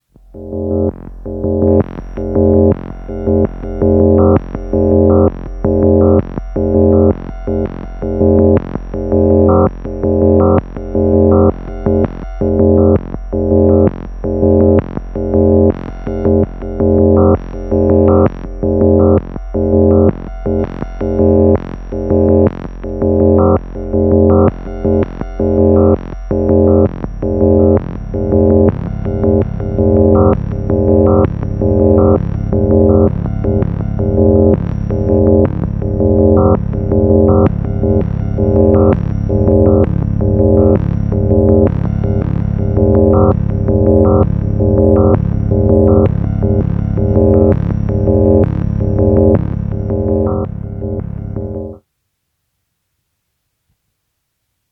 No filter, no sequencer, no enevelopes, no triggers, just 2 lfos and an oscillator.
But both LFOs running at different rates. So there’s that weird rhythmic thing.